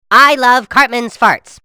Farts Pt1 Sound Effect Free Download